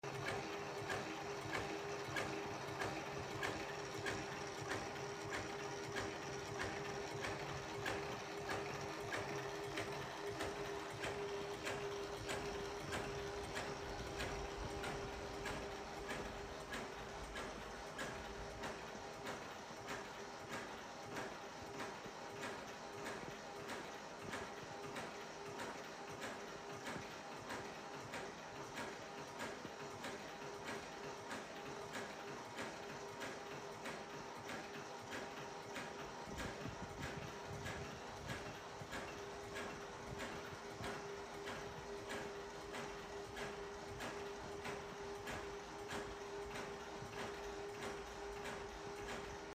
CODE3 MX 7000 INTERSECTION SWEEP sound effects free download